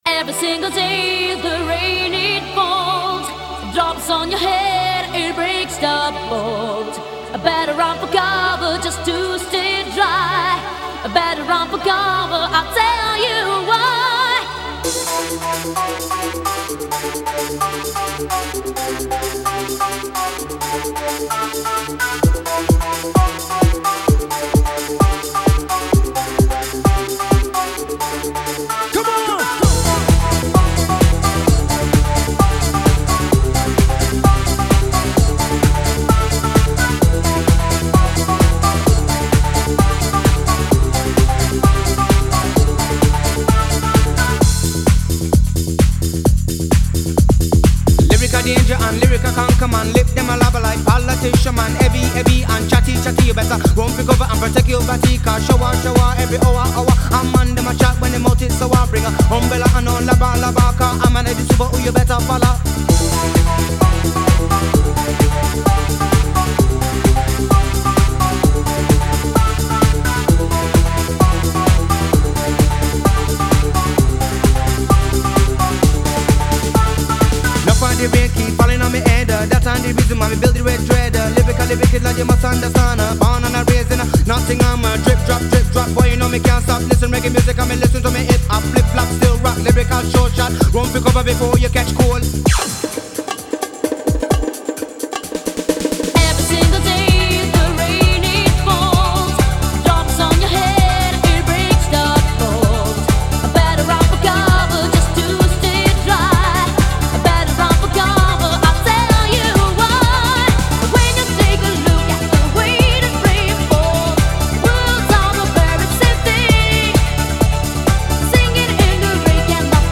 Genre: Eurodance.